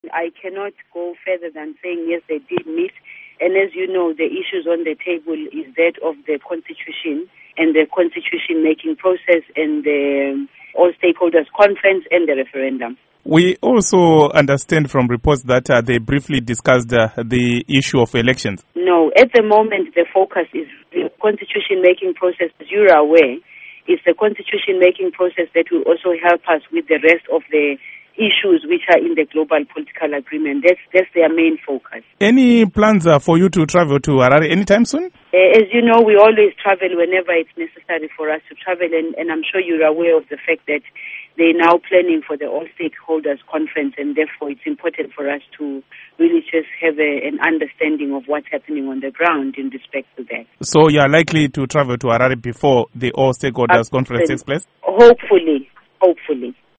Interview With Lindiwe Zulu